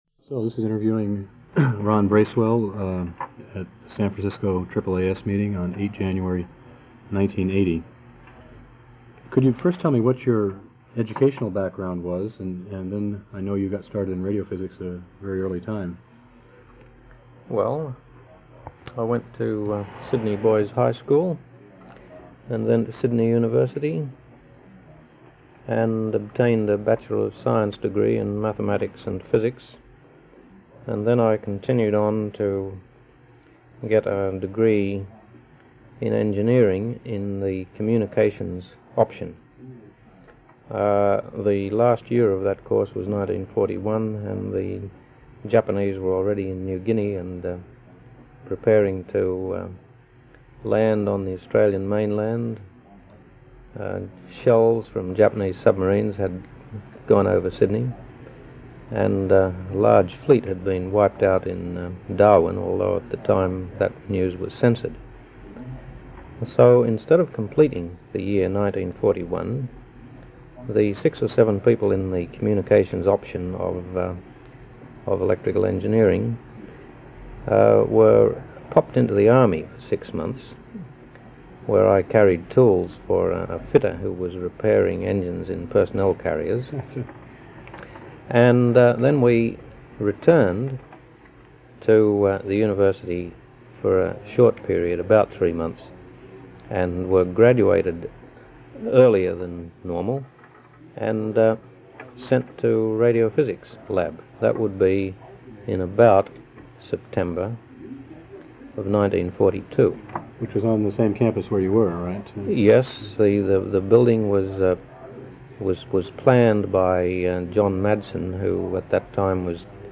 Interview with Ronald N. Bracewell